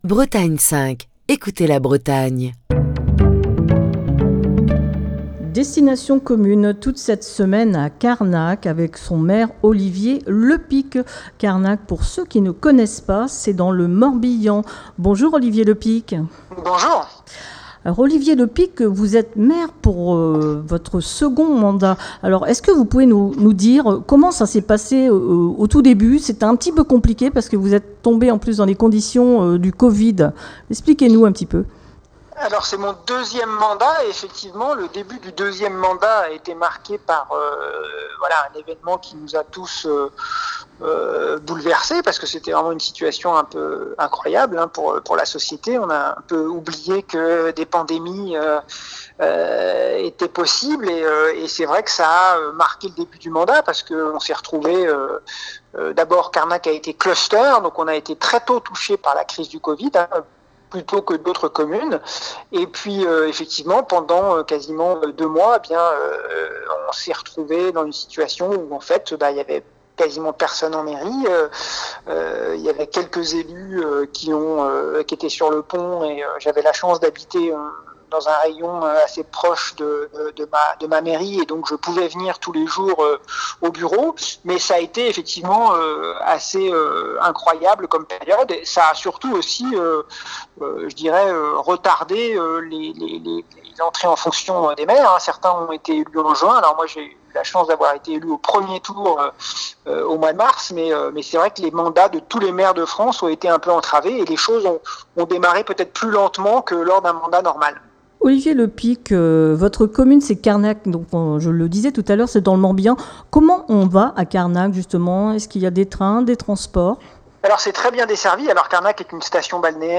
Cette semaine, Destination commune pose ses micros à Carnac, dans le Morbihan.